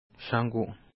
Pronunciation: ʃ